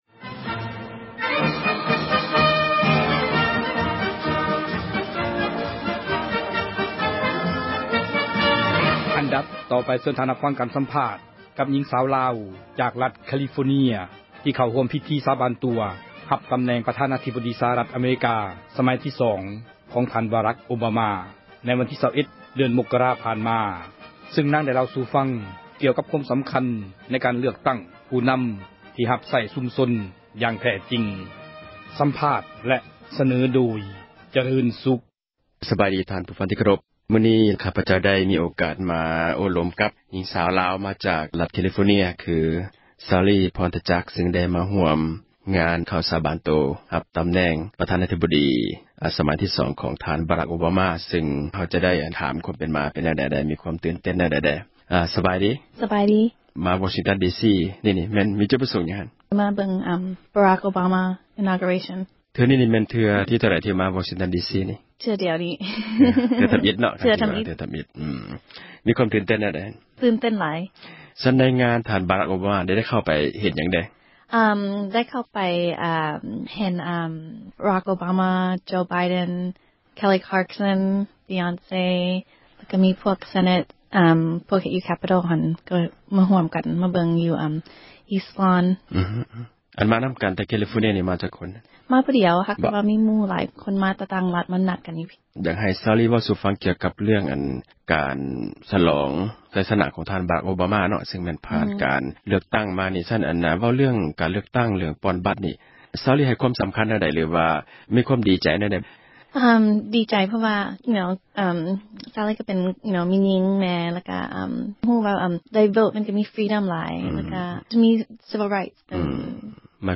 ສຳພາດຍິງສາວລາວ ໃນການເລືອກຜູ້ນໍາ
ເຊີນທ່ານ ຮັບຟັງ ການສຳພາດ ກັບ ຍິງສາວລາວ ຈາກຣັຖ California ທີ່ເຂົ້າຮ່ວມ ພິທີ ສາບານຕົວ ຮັບຕຳແໜ່ງ ປະທານາທິບໍດີ ສະຫະຮັຖ ສມັຍທີ່ສອງ ຂອງທ່ານ ”ບາຣັກ ໂອບາມາ” ໃນວັນທີ 21 ມົກກະຣາ ຜ່ານມາ, ຊື່ງນາງ ໄດ້ເລົ່າສູ່ຟັງ ກ່ຽວກັບ ຄວາມສຳຄັນ ໃນການເລືອກຕັ້ງ ຜູ້ນຳ ທີ່ຮັບໃຊ້ ປະຊາຊົນ ຢ່າງແທ້ຈິງ. ສເນີໂດຍ